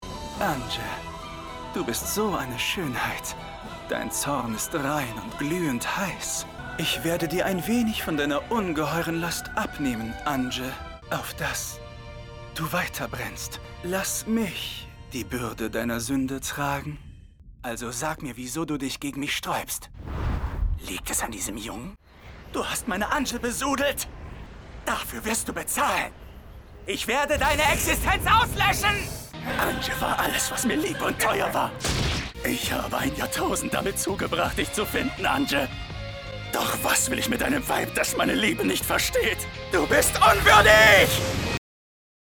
Synchron Referenz Anime „Cross Ange“
Rolle „Embryo“ – aristokratisch, androgyn